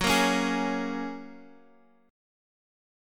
Gbadd9 chord